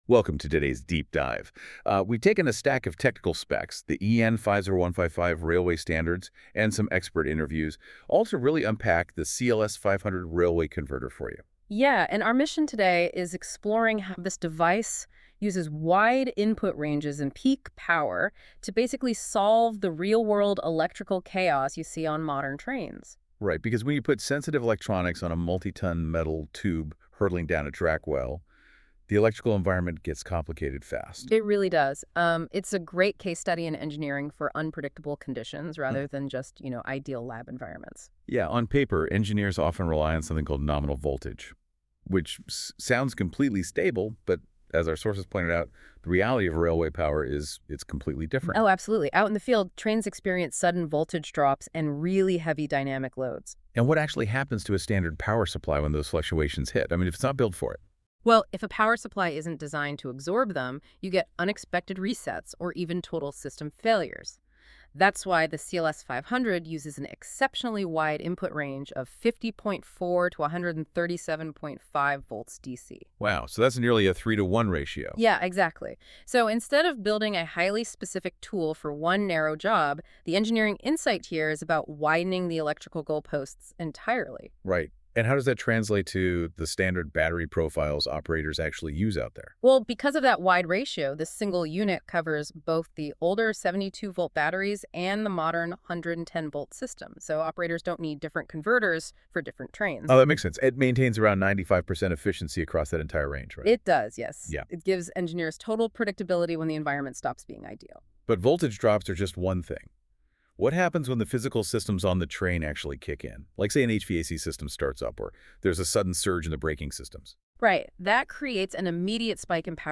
A lo largo de la conversación, el experto invitado explica por qué el rendimiento real de un sistema de potencia se define por su comportamiento en los extremos, no en el punto nominal.